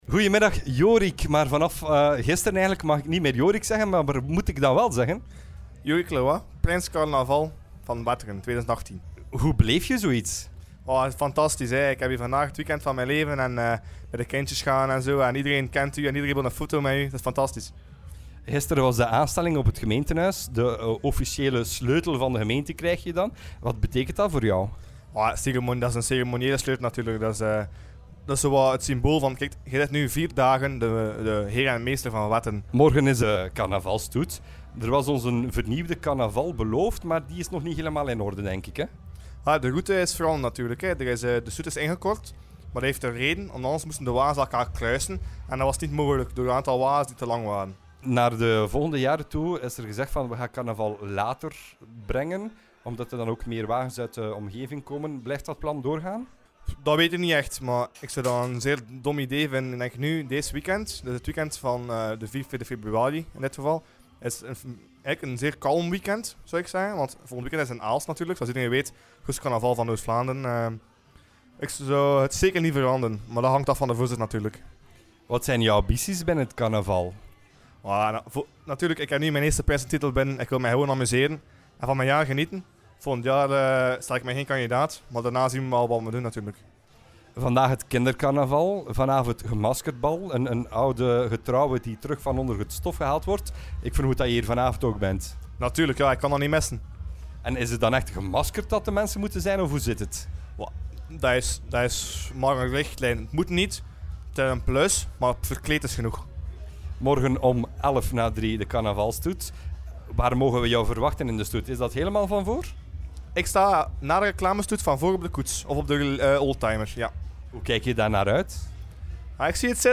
kindercarnaval_mixdown.mp3